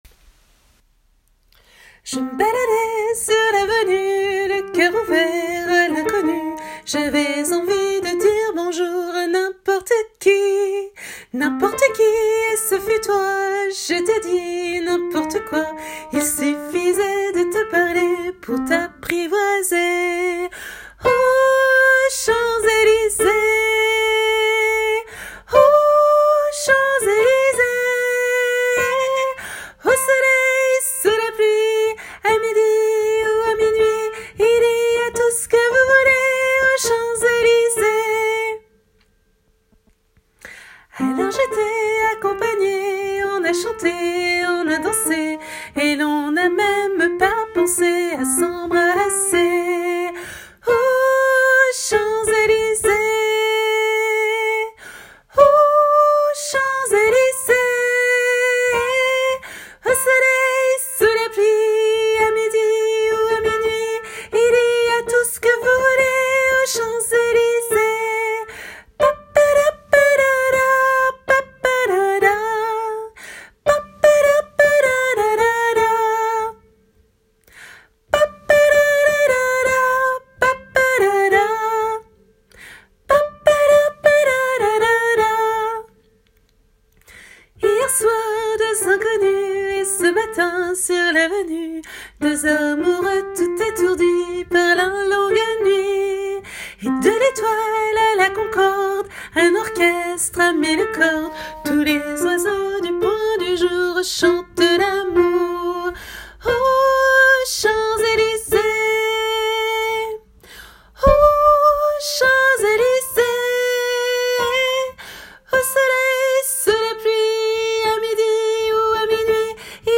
MP3 versions chantées (les audios sont téléchargeables)
Tenor